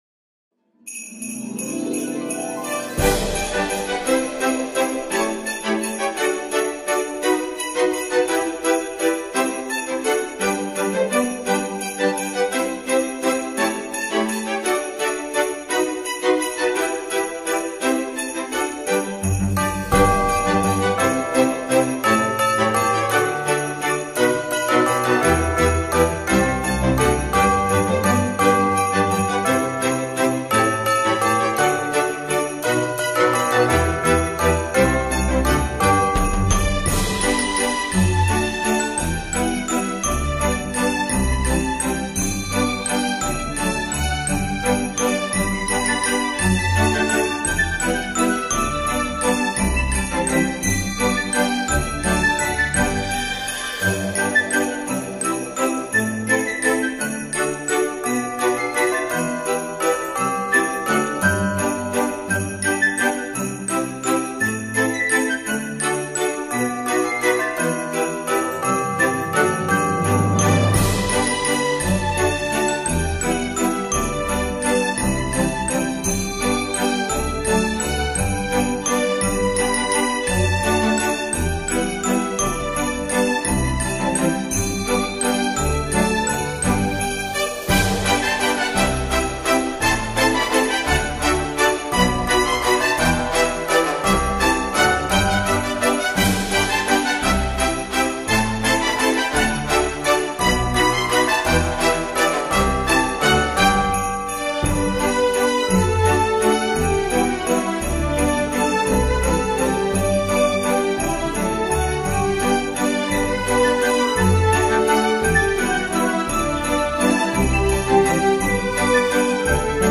christmas_instrumental_music.mp3